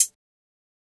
Closed Hats
HiHat (23).wav